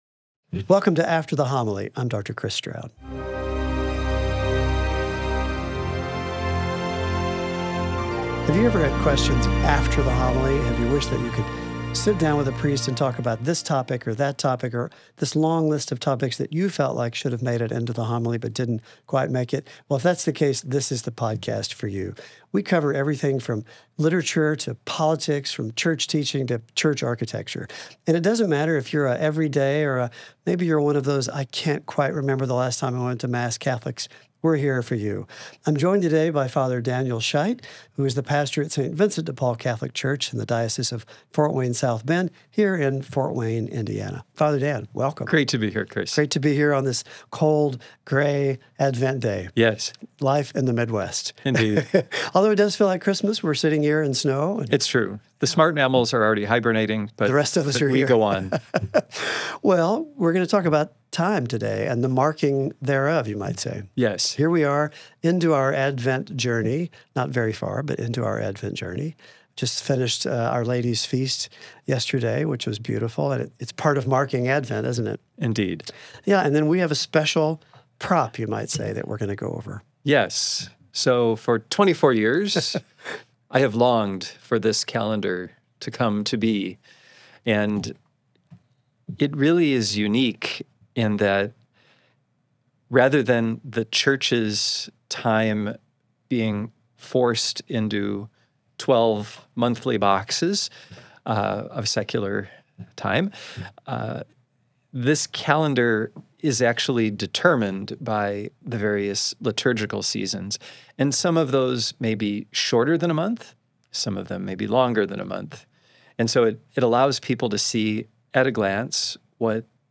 The conversation explores why modern life makes slowing down so difficult and practical ways to align our daily lives with the liturgical seasons, transforming time from something that enslaves us into a pathway for encountering Christ.